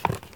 Helmet and visor sounds
helmet_drop1.ogg